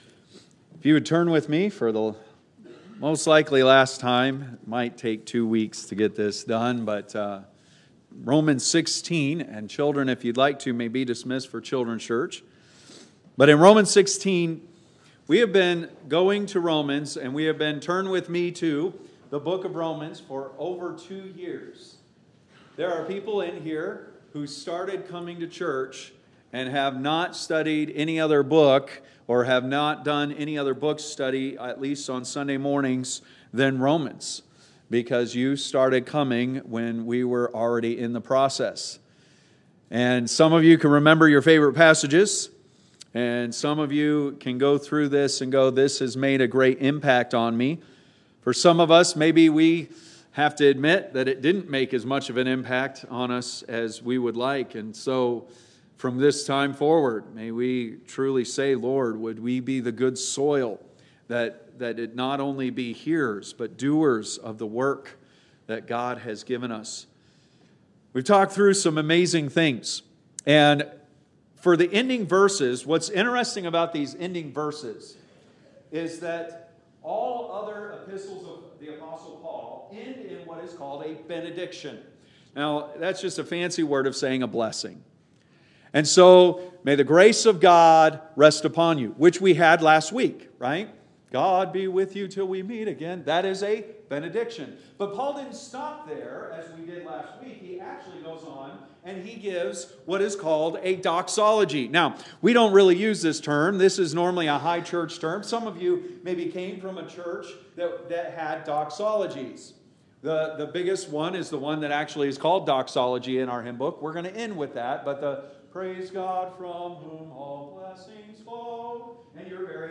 Date: February 22, 2026 (Sunday Morning)